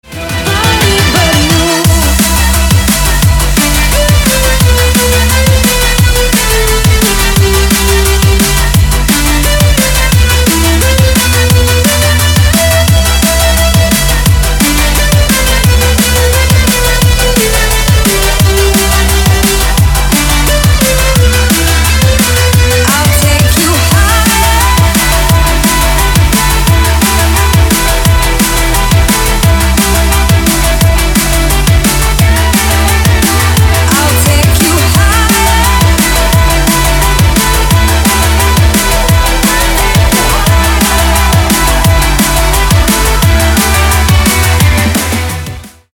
женский голос
Electronic
club
drum n bass
DnB